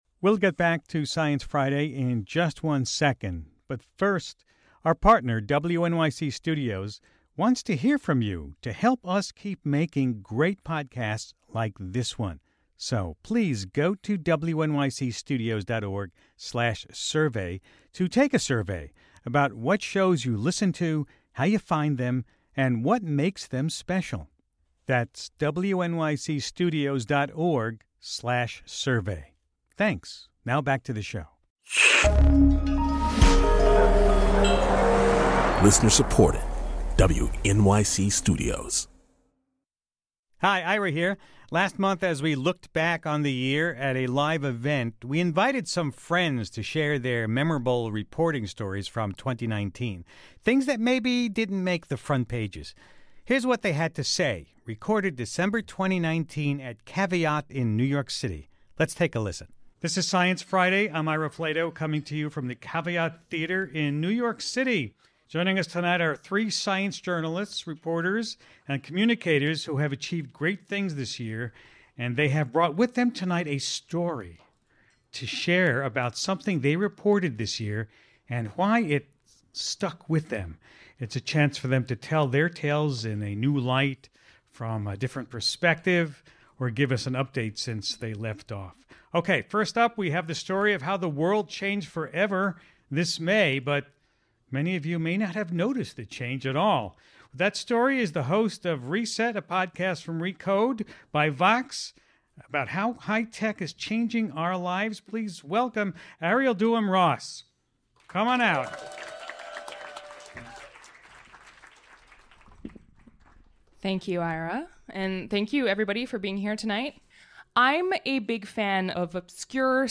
At our year in review event at Caveat in NYC on December 18, 2019, three science storytellers